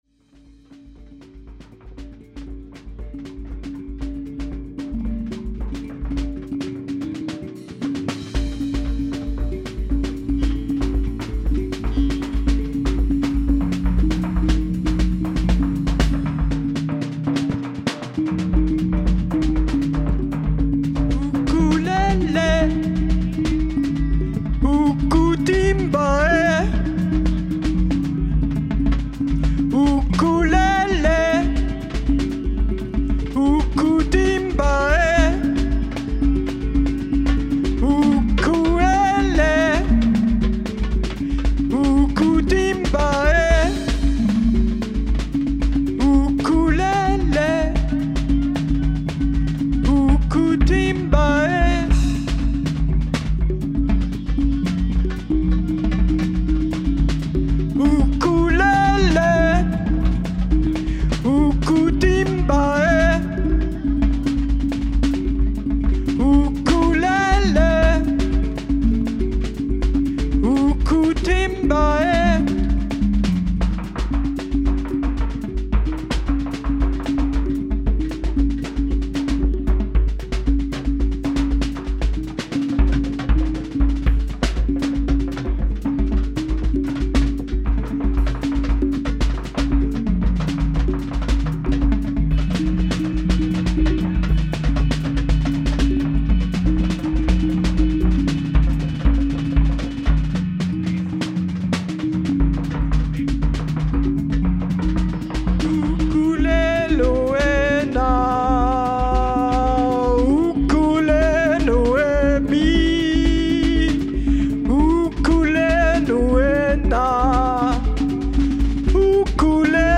Neu abgemischt und gemastert, speziell für Vinyl.